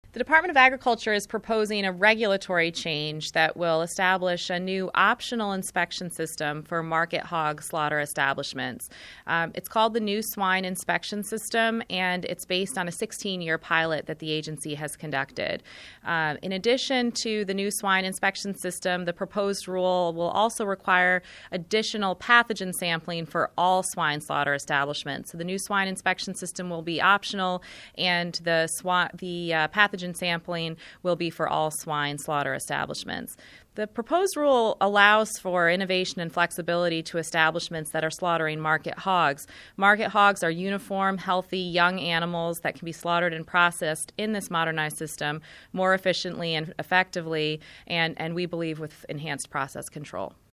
Listen to radio actualities from Acting Deputy Under Secretary Carmen Rottenberg: